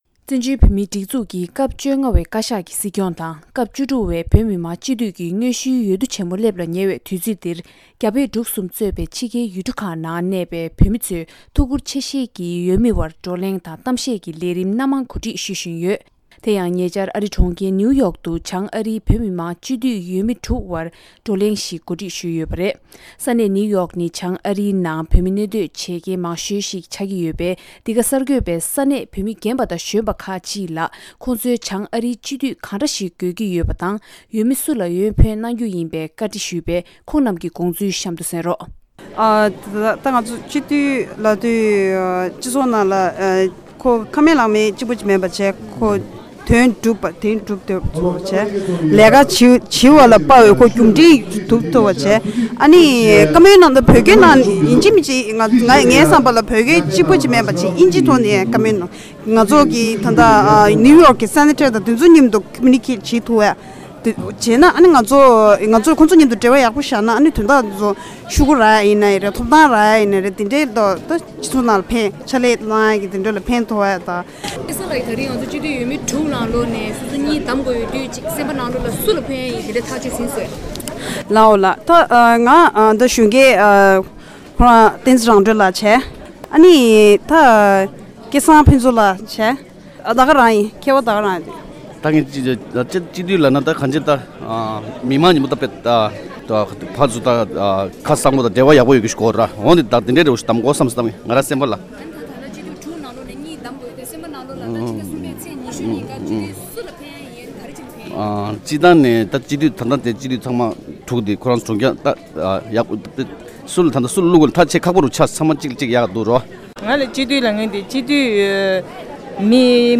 ༄༅།།ཨ་རིའི་གྲོང་ཁྱེར་ནེའུ་ཡོག་ནང་བྱང་ཨ་རིའི་སྤྱི་འཐུས་འོས་མིའི་དབར་བགྲོ་གླེང་གནང་བའི་རྗེས་མི་མང་གི་བསམ་ཚུལ་བཀའ་འདྲི་ཞུས་པའི་ལས་རིམ་ཞིག